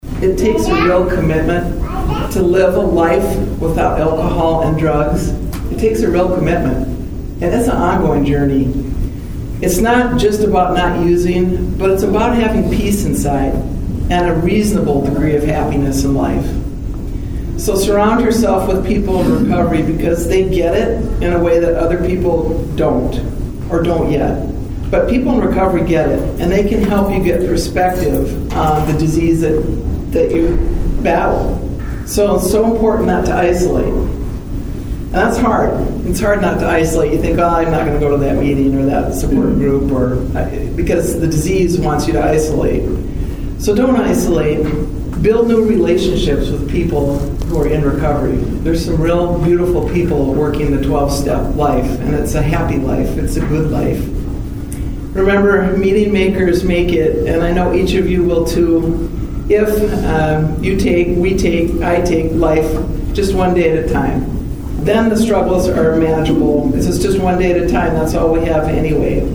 South Dakota Supreme Court Justice Janine Kern speaks at the Sixth Circuit Drug and DUI Treatment Court Program Graduation in Pierre Nov. 12, 2025.